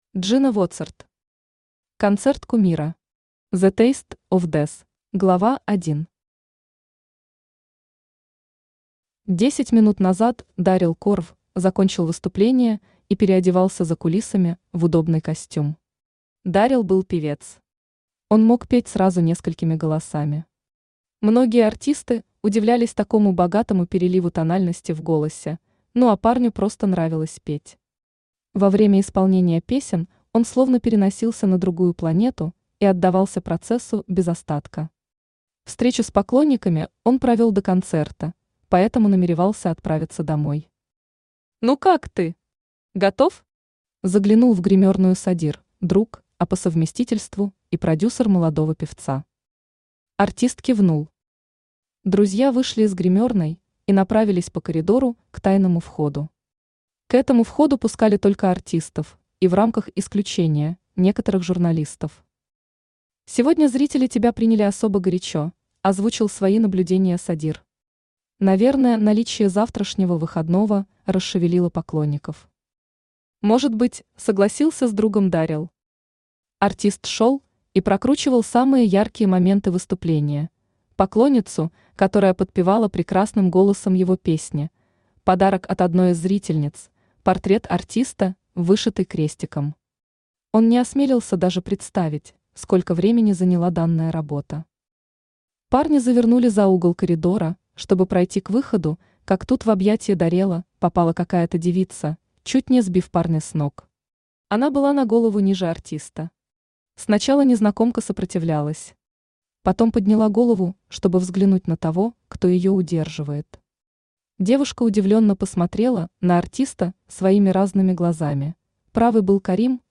Аудиокнига Концерт кумира. «The taste of death» | Библиотека аудиокниг
«The taste of death» Автор Джина Воцард Читает аудиокнигу Авточтец ЛитРес.